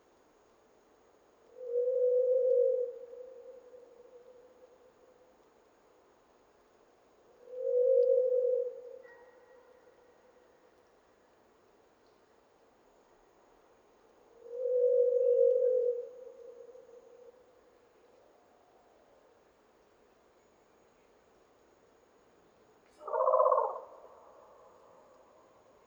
Pacific Pigeon (Rupe) – It’s Call
The two calls of the pigeon are: (1) a barking growl “RRRRRRRR”, and (2) a rather uniform coo “OOOOOOOOO”.